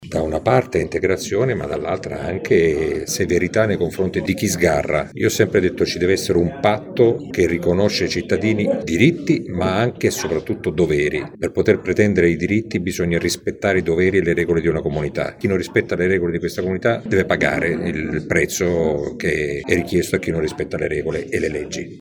Da capire ora dove troveranno spazio queste persone, sulla loro futura integrazione sentiamo proprio il sindaco di Modena, Mezzetti: